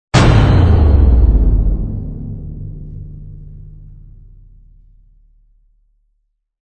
我们的第二种声音设计通过更大，更糟的鼓，上升，打击和基本恐怖元素来释放前奏。